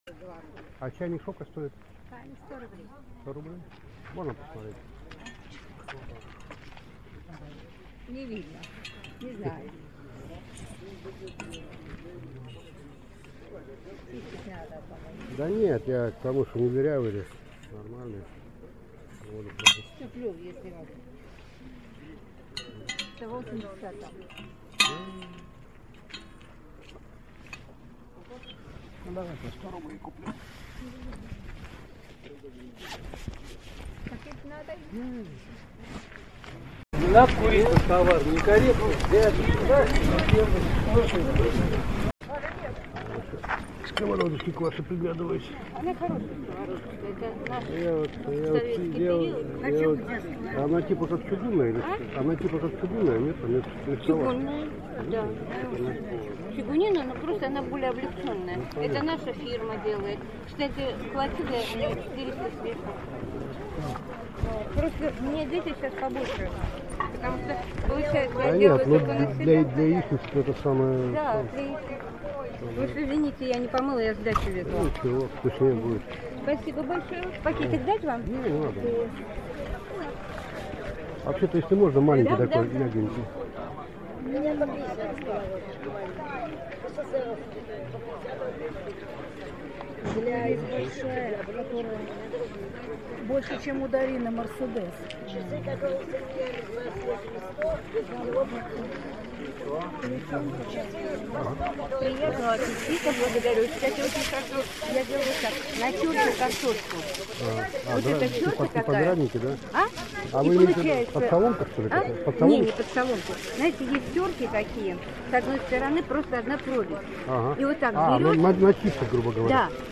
На этой странице собраны разнообразные звуки рынка: гул толпы, крики продавцов, стук товаров, смех покупателей.
Атмосферный гул блошиного рынка